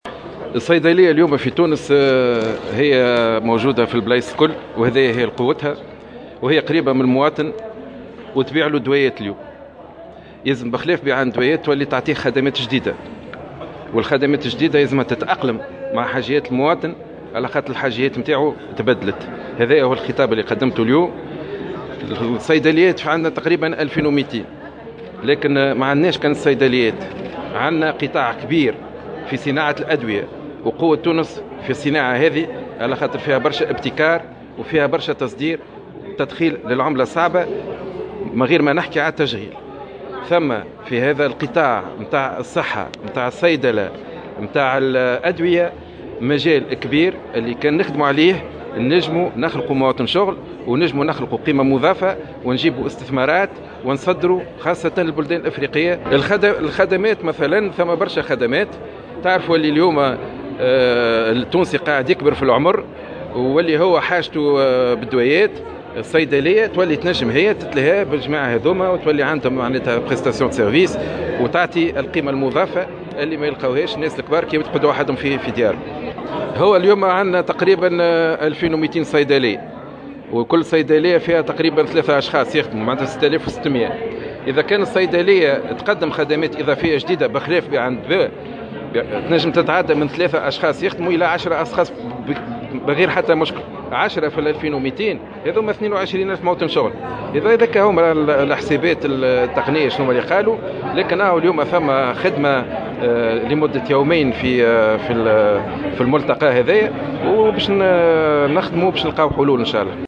قال وزير الصحة سليم شاكر في تصريح لمراسلة الجوهرة اف ام، إنه لا بد من إضفاء خدمات جديدة داخل الصيدليات تستجيب إلى حاجيات المواطن، إلى جانب بيع الأدوية.